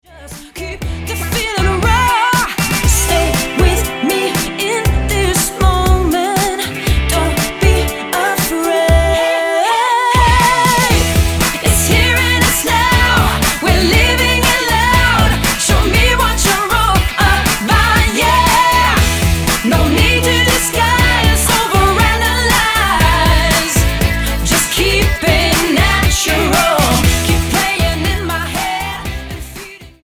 dance floor hit